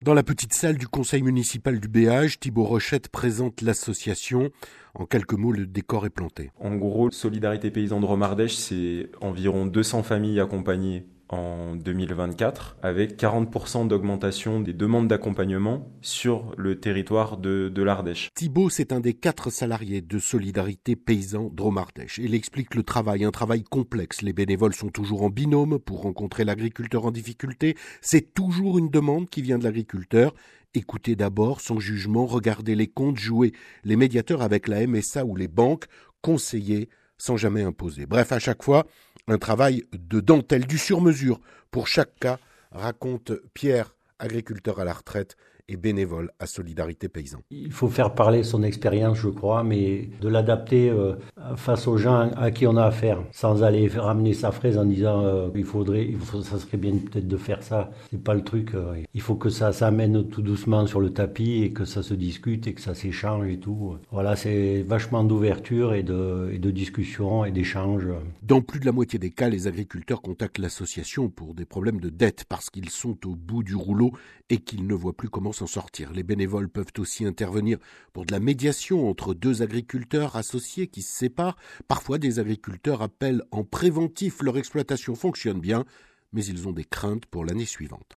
- Reportage lors d'une rencontre d'accueil de nouveaux bénévoles sur le plateau ardéchois au Béage, à écouter